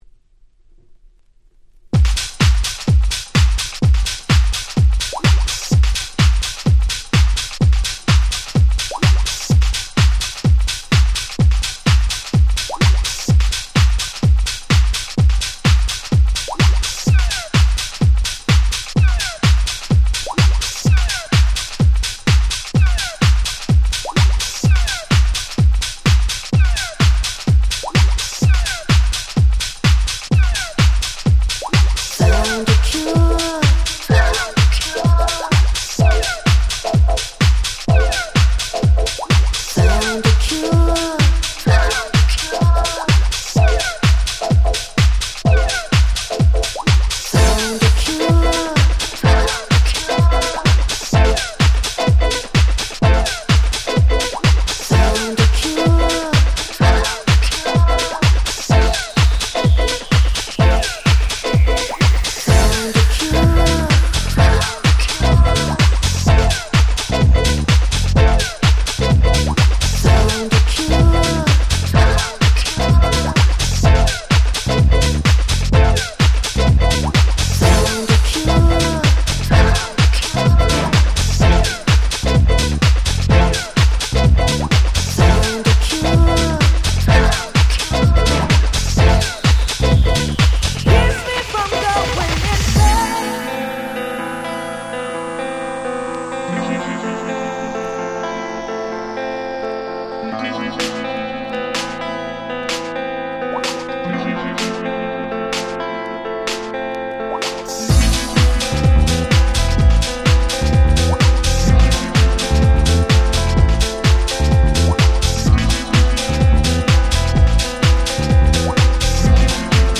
98’ Smash Hit Vocal House !!
サビで一気にブチ上がり！！